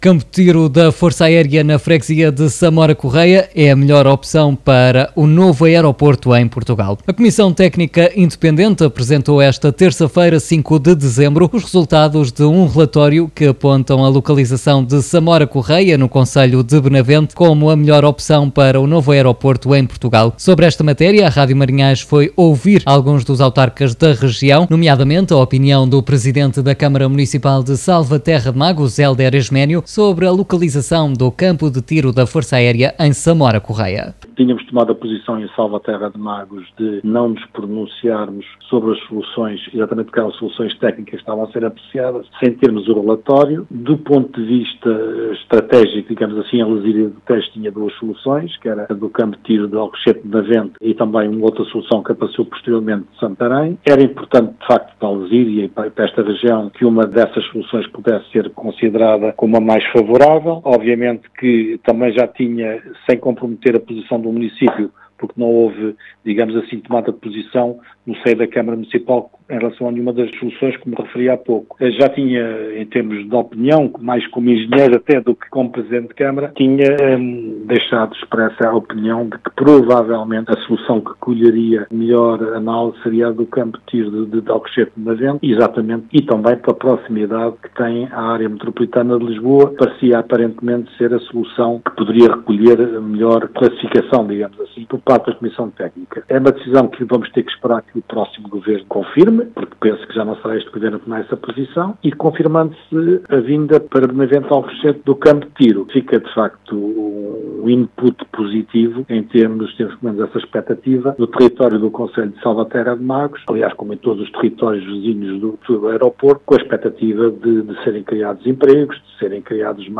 RM Entrevista: “Era importante para a Lezíria que uma das opções – Alcochete ou Santarém – pudesse ser considerada”, afirma Hélder Esménio (c/ som)
Escute, aqui, as declarações de Hélder Manuel Esménio à antena da MarinhaisFM no dia 5 de dezembro de 2023: